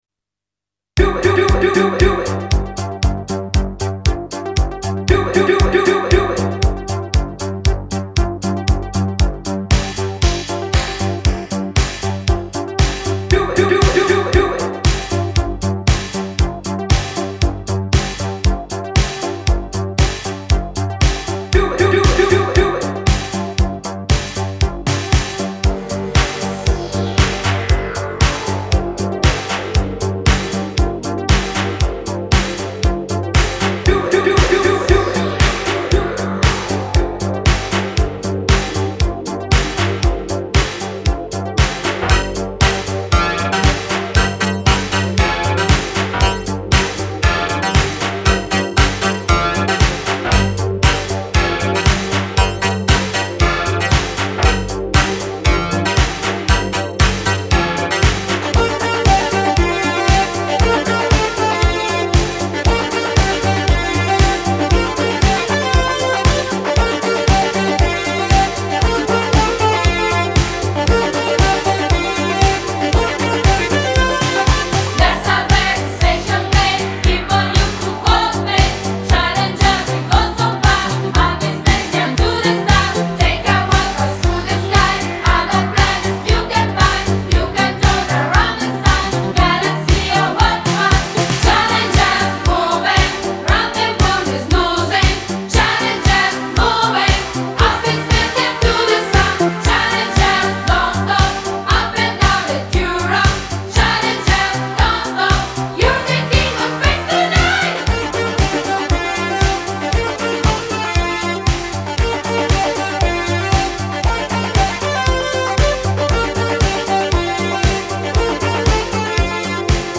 Галерея Music Disco 80